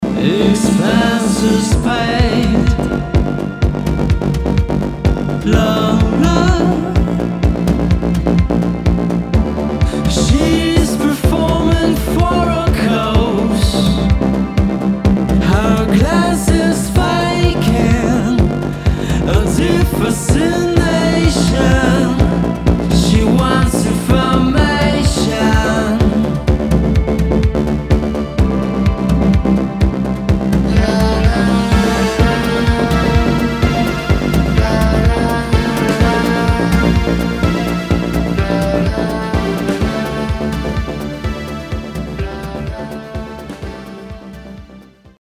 italopop